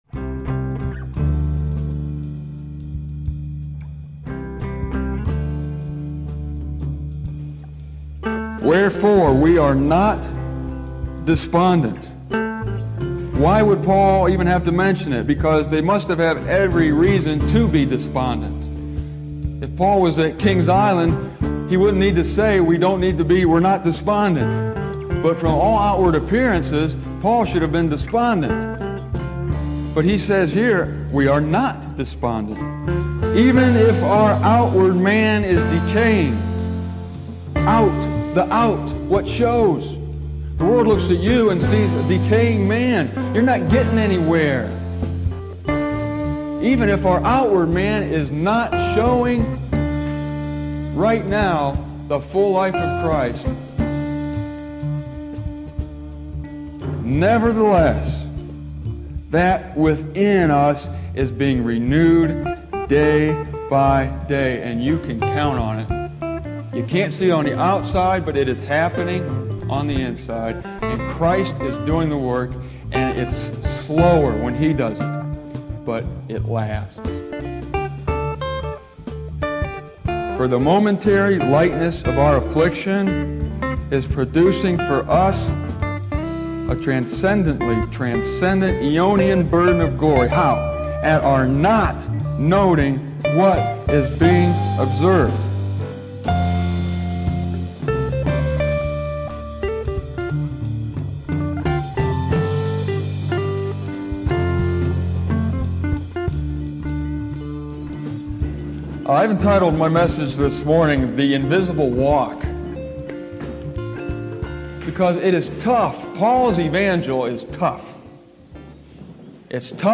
This theme is explored in a video I made in June of 2005 in Willard, Ohio.